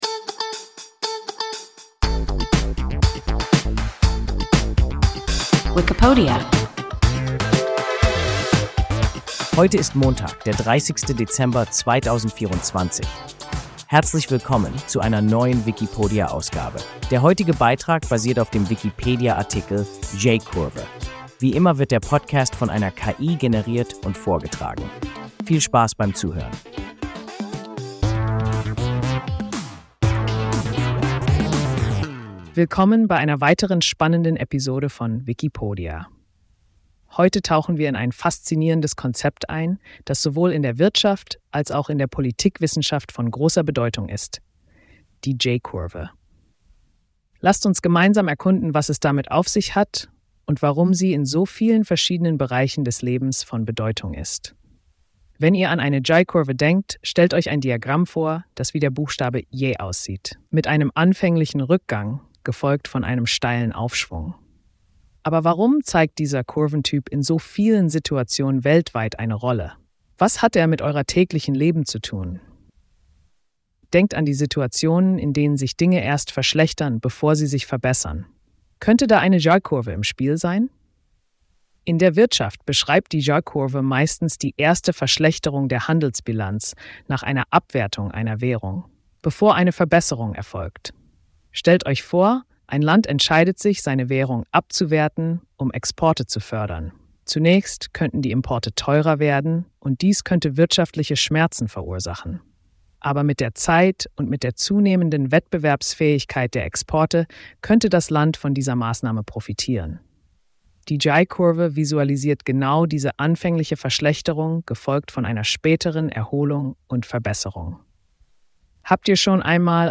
J-Kurve – WIKIPODIA – ein KI Podcast